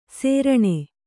♪ sēraṇa